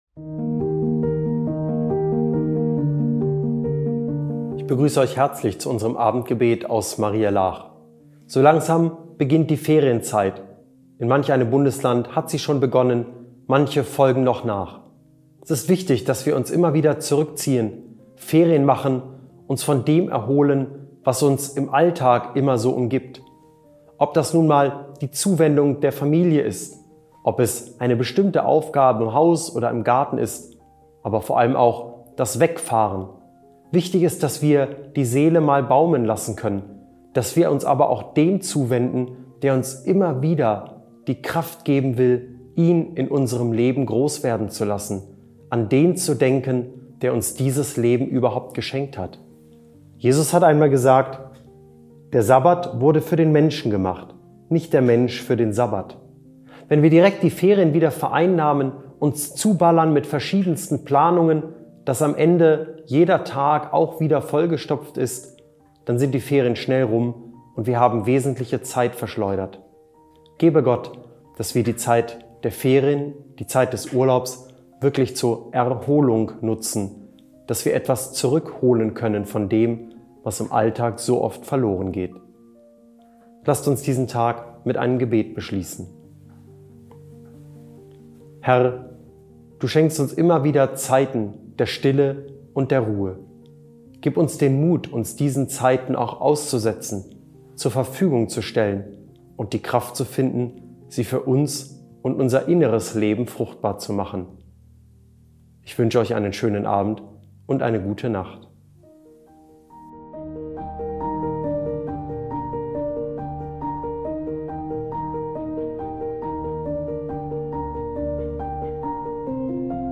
Abendgebet – 5. Juli 2025